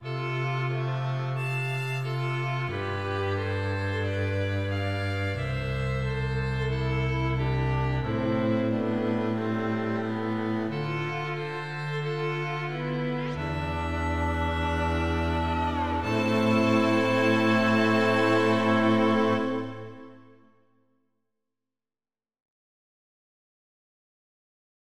As you can determine from "Ode To A Mode", there is a B for the Solo Violin at the start of the 2nd measure, and it is played correctly .
P. S. This is the WAVE audio file (WAV, 4.5MB, approximately 24 seconds) for the version that has the London Symphony Orchestra Solo Violin VSTi, which is doing glissandi, which I like .